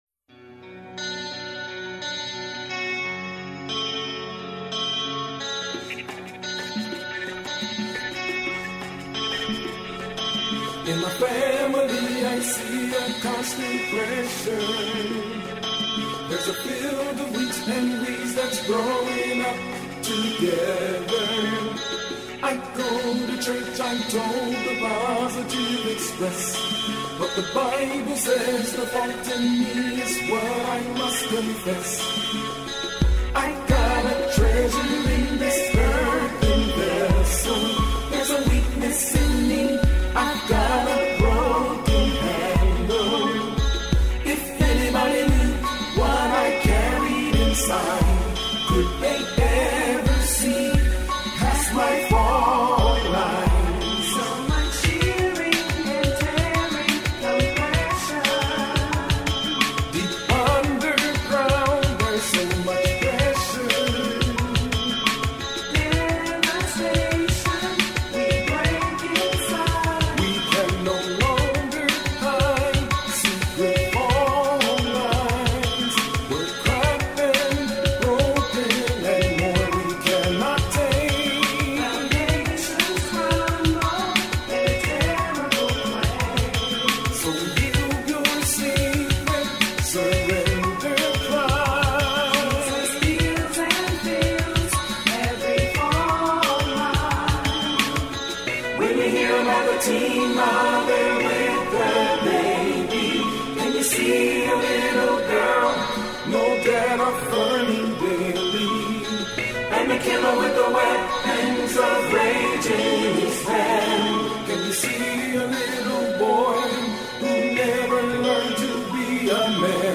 uniquely, simplistic style